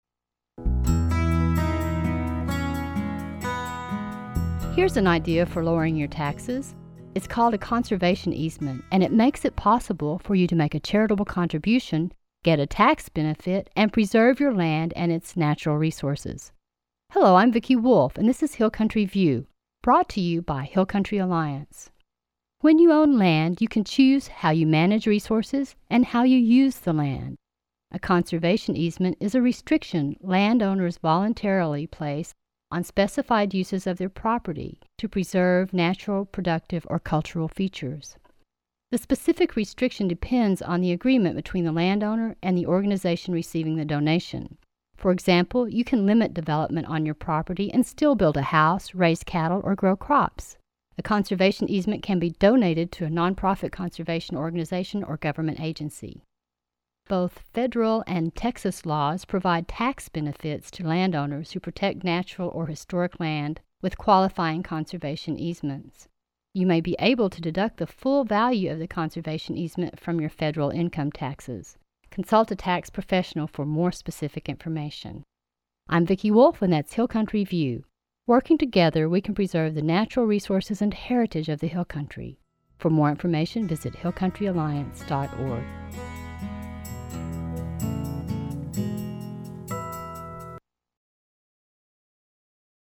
90 Second Radio Spots